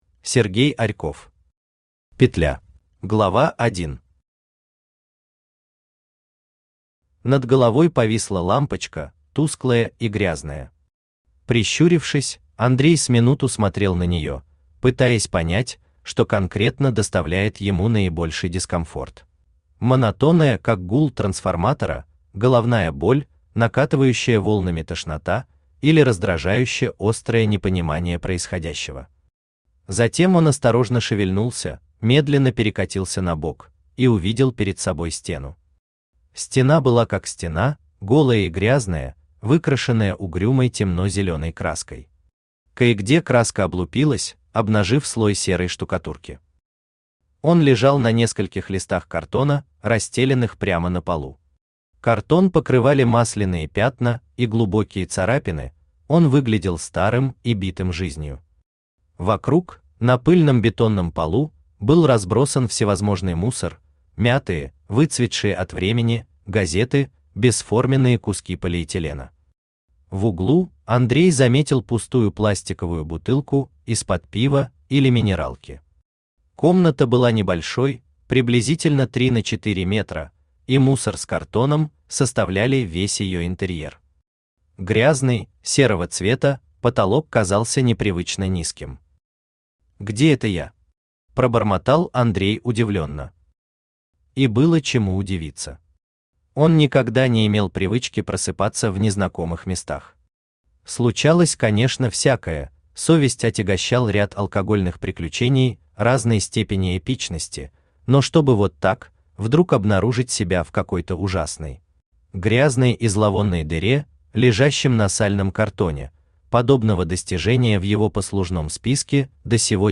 Aудиокнига Петля Автор Сергей Александрович Арьков Читает аудиокнигу Авточтец ЛитРес. Прослушать и бесплатно скачать фрагмент аудиокниги